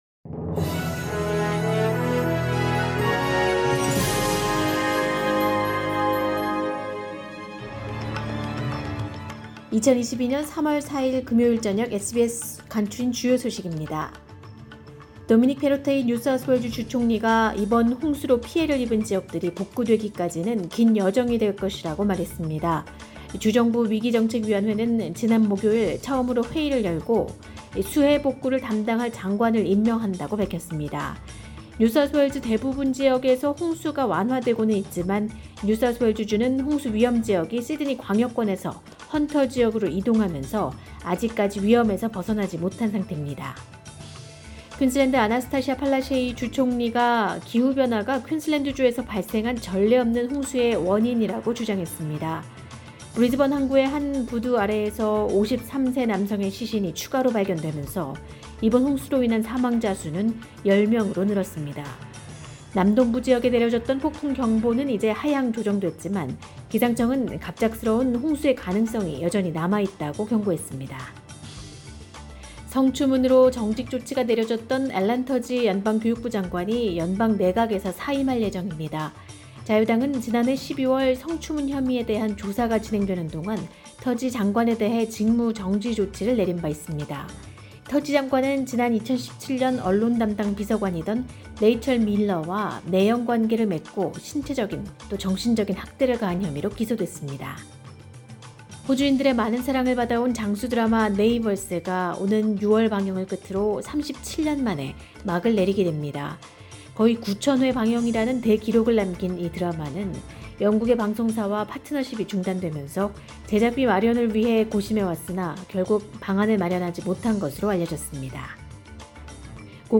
2022년 3월 4일 금요일 저녁의 SBS 뉴스 아우트라인입니다.